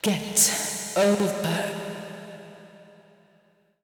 House / Voice / VOICEGRL096_HOUSE_125_A_SC2.wav